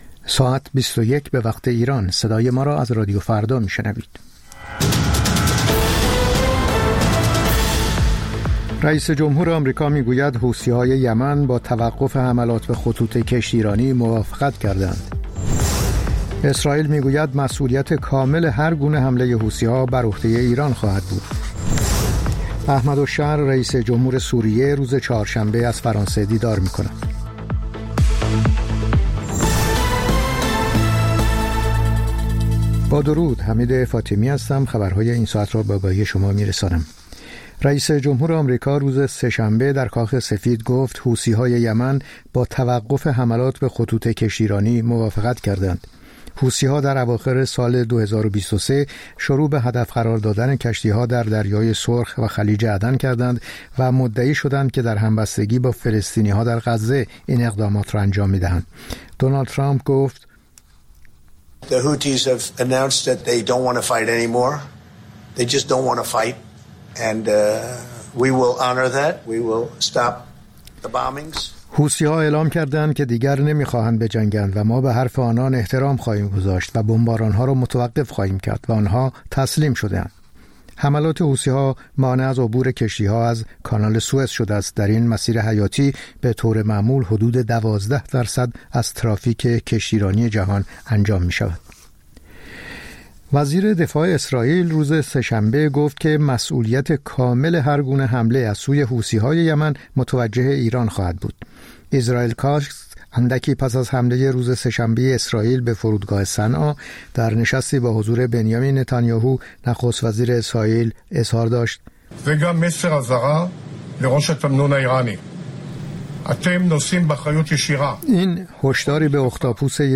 سرخط خبرها ۲۱:۰۰